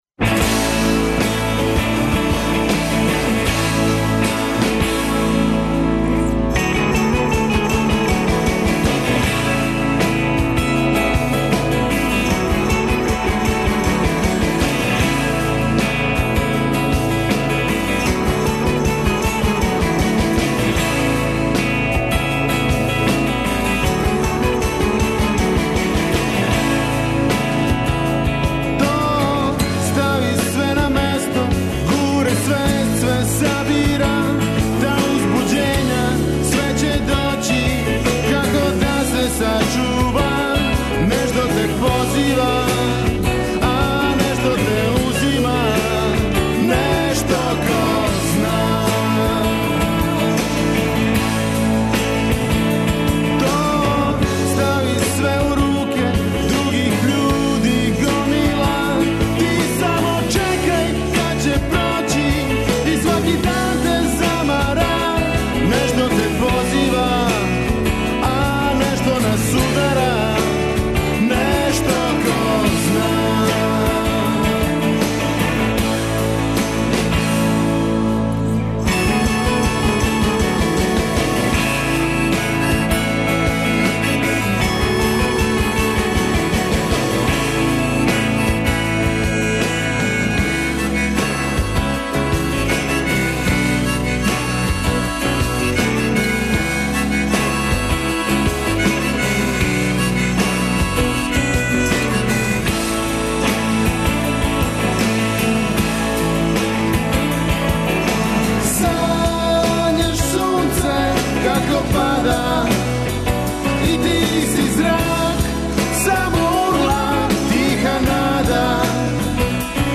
Гост емисије је познати хрватски певач Давор Радолфи, који је најавио концерт у Београду 21. марта.